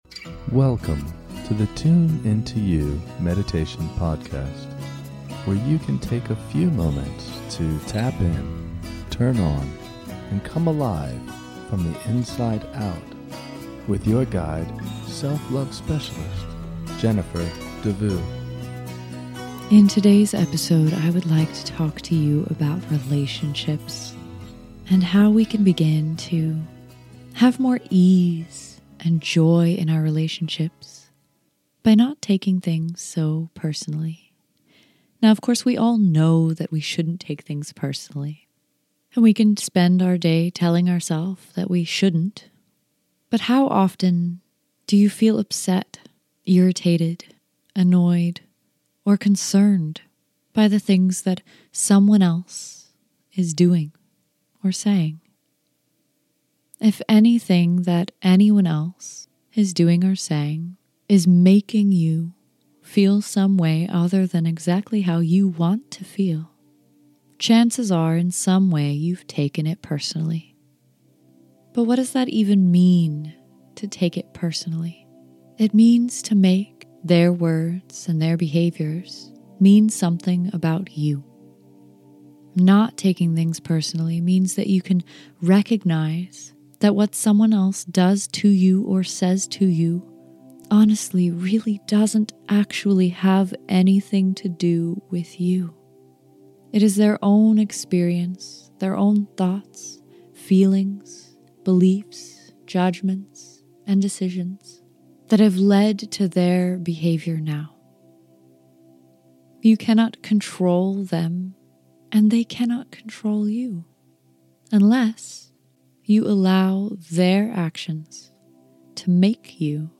In this short guided meditation, we will learn how to not take things personally. It is easy to take things personally in our relationships.